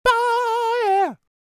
DD FALSET078.wav